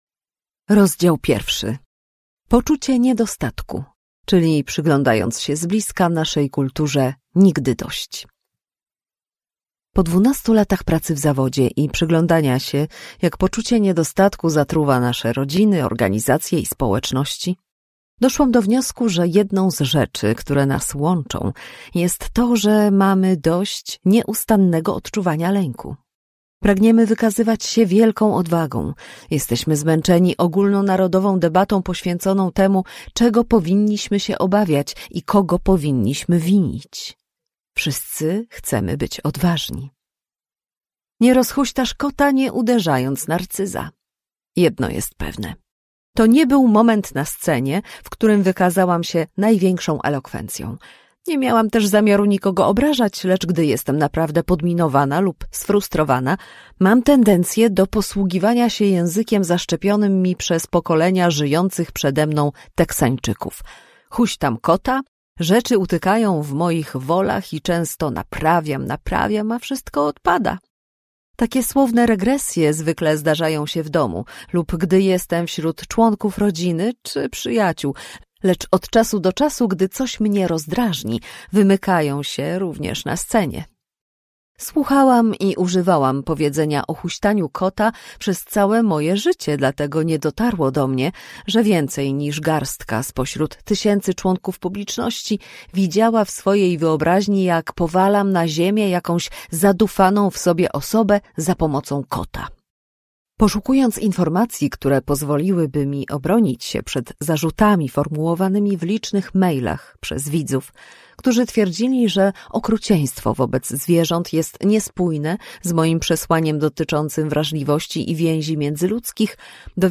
AUDIOBOOK (mp3) Jak odwaga bycia wrażliwym zmienia to, jak żyjemy i kochamy, jakimi rodzicami i liderami jesteśmy Pobierz fragment -35% 49.90 zł 32.44 zł Najniższa cena z 30 dni przed obniżką: 24.95 zł